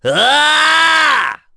Roman-Vox_Casting4.wav